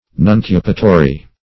Nuncupatory \Nun*cu"pa*to*ry\, a.